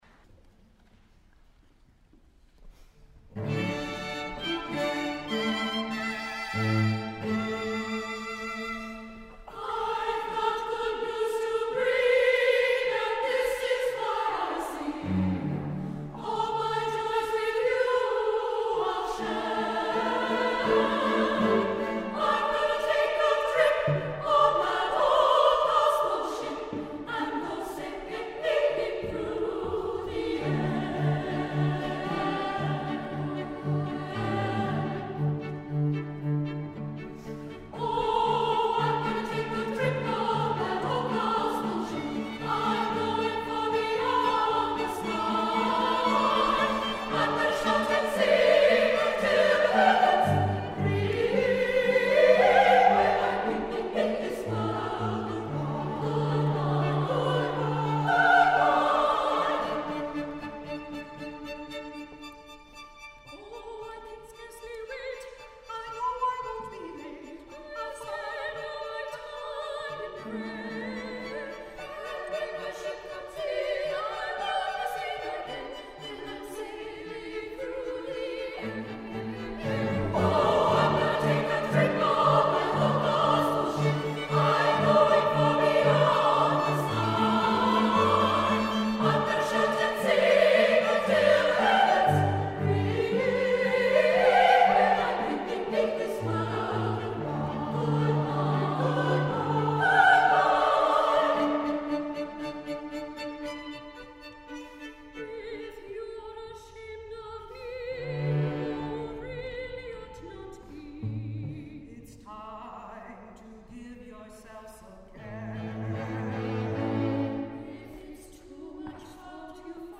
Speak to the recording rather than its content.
(MP3 of SSA performance)